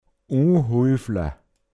pinzgauer mundart
u(n)huifia unhandlich, unbequem, unbeholfen